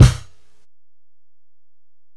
Kick (22).wav